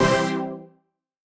Index of /musicradar/future-rave-samples/Poly Chord Hits/Straight
FR_PHET[hit]-C.wav